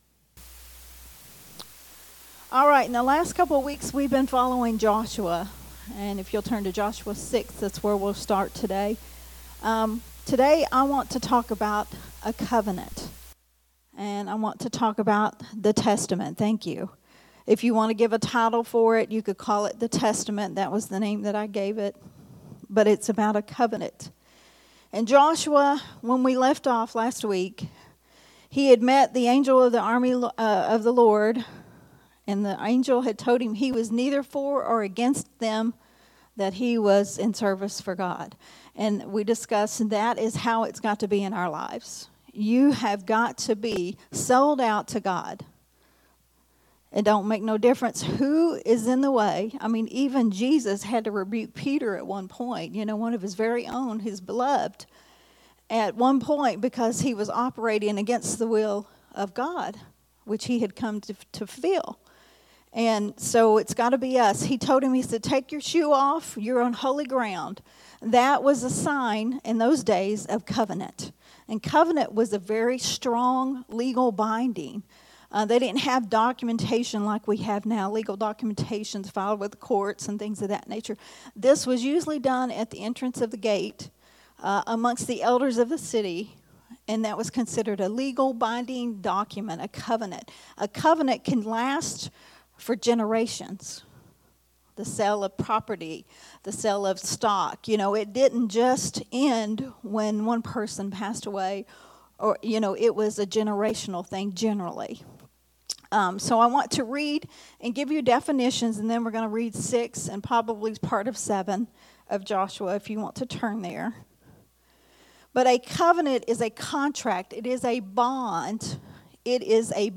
recorded at Unity Worship Center on Sunday, March 10th, 2024.